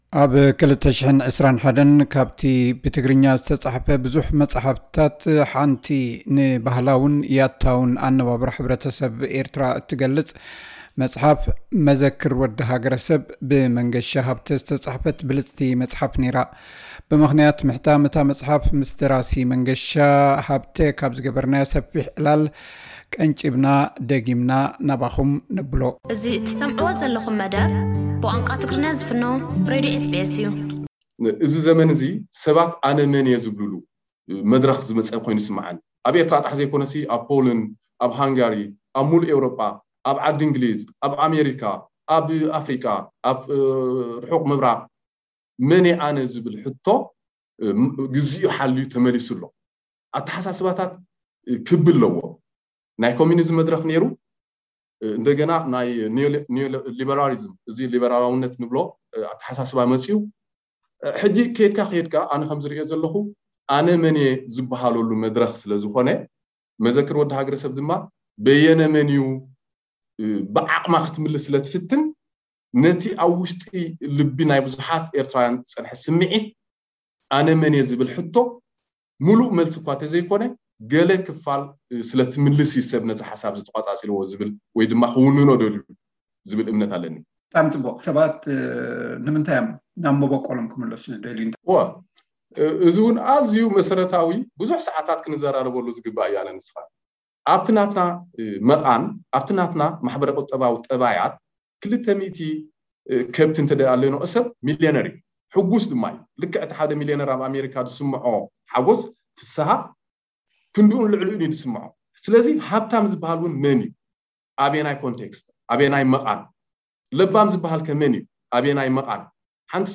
ሰፊሕ ዕላል